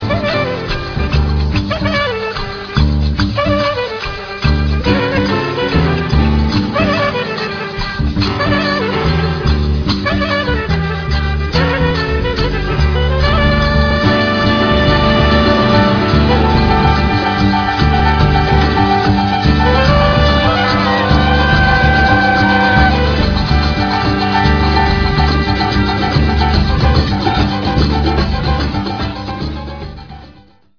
live, 2002
percussion
piano
saxophone & grinata
trumpet & flugelhorn
percussion & vocal group: